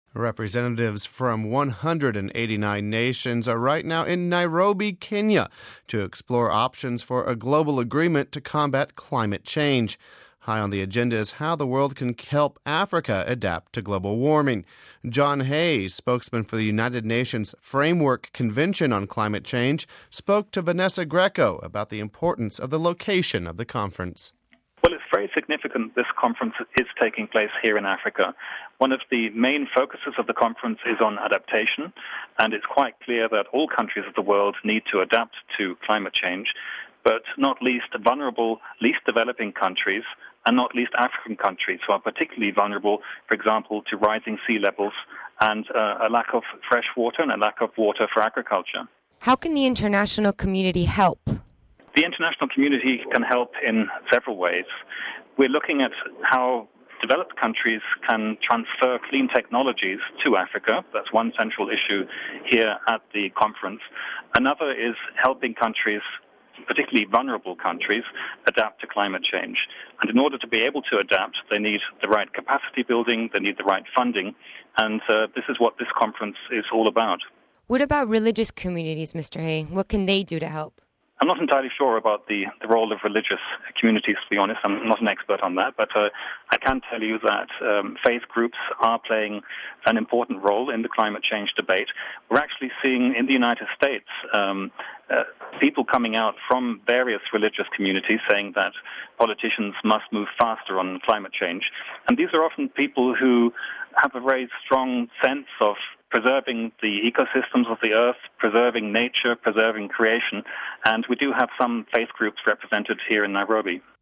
Home Archivio 2006-11-08 16:20:32 Climate Change Conference in Kenya (8 Nov 06 - RV) The United Nations is holding a meeting on climate change for the next two weeks in Nairobi. We spoke to one official organizing the event...